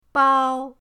bao1.mp3